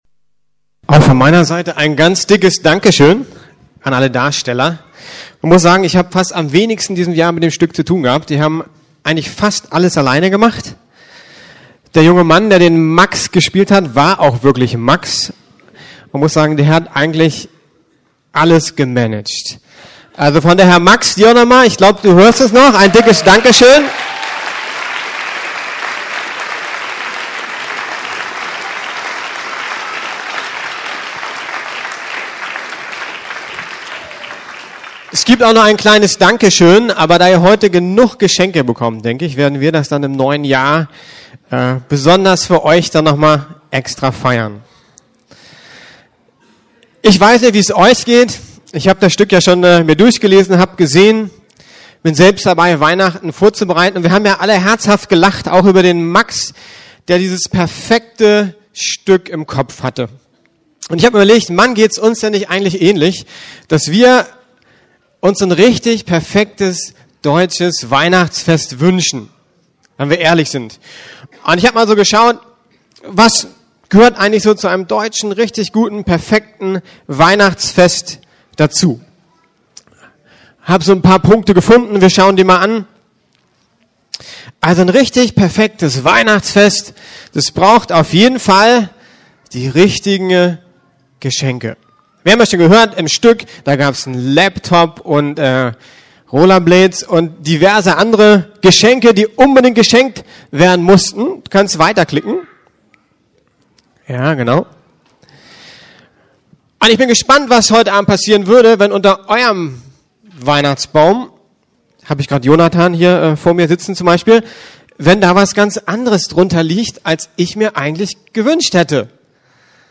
Familiengottesdienst mit Weihnachtsstück